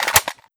Index of /fastdl/sound/weapons/ak103
slideforward.wav